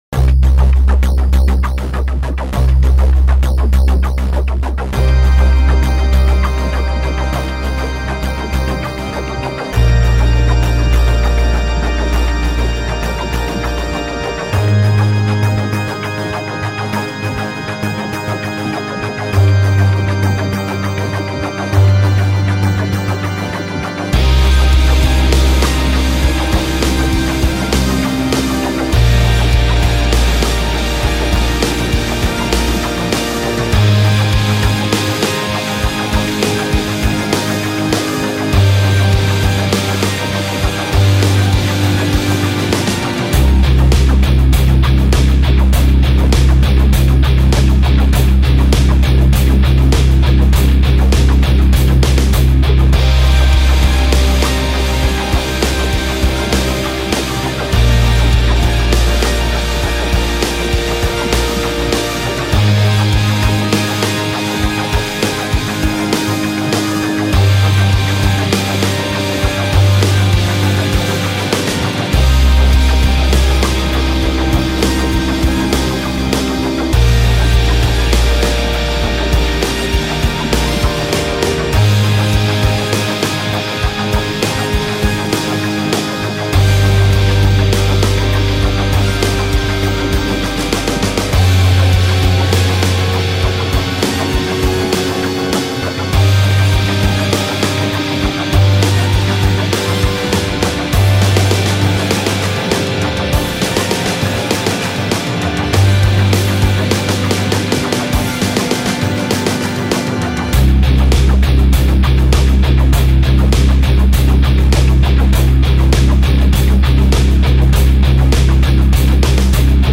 フリーBGM ダンジョン
despairloop ogg